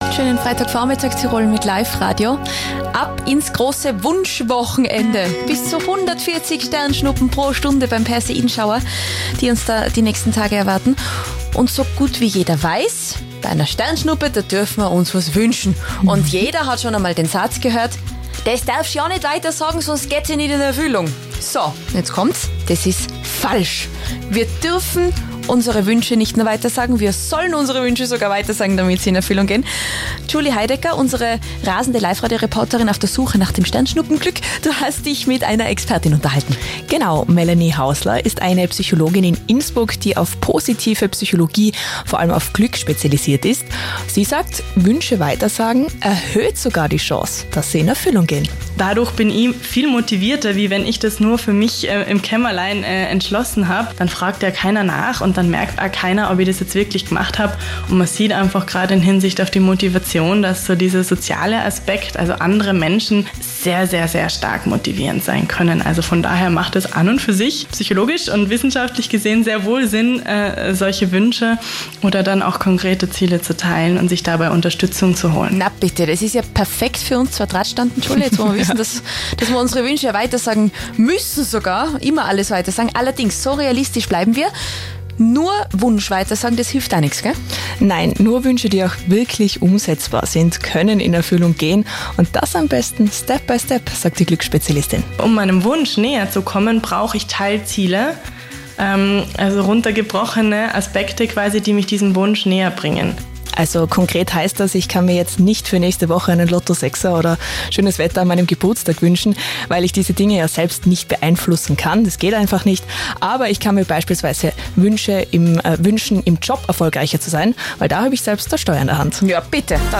interview_live_radio_tirol.mp3